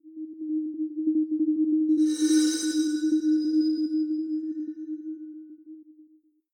game key level unlock video sound effect free sound royalty free Gaming